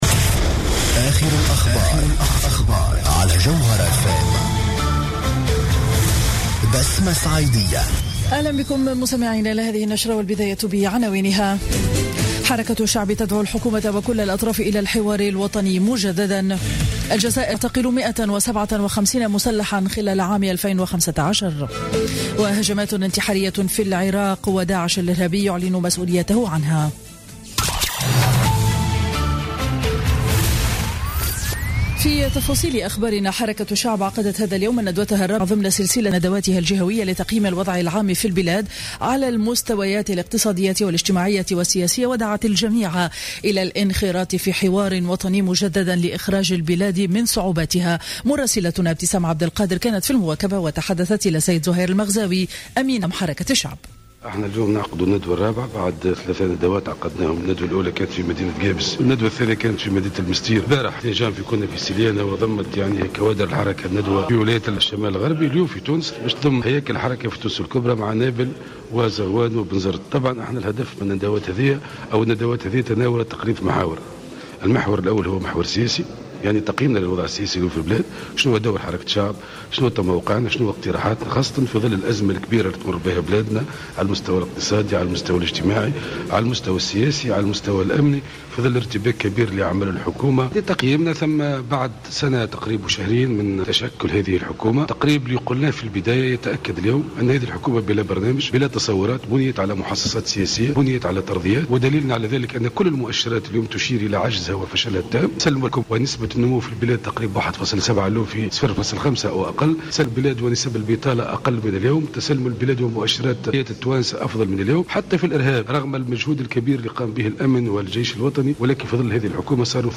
نشرة أخبار منتصف النهار ليوم الأحد 3 جانفي 2016